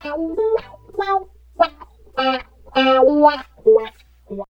71 GTR 3  -L.wav